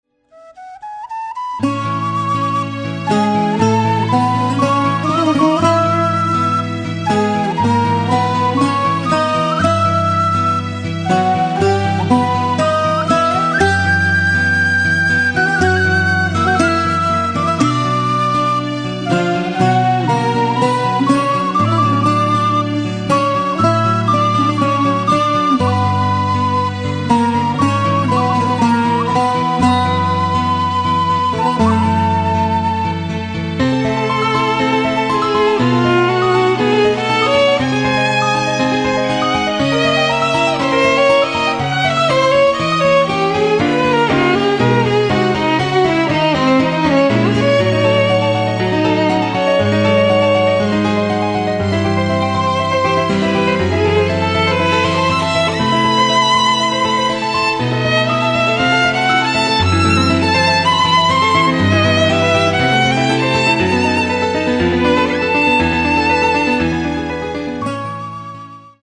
センチメンタルなメロディが涙腺刺激しまくる感動の名作
Acoustic Bass
Bouzouki
Duduk
Violin, Viola
基本的には素朴なトラッドだが、エキゾチックな演奏が素晴らしい。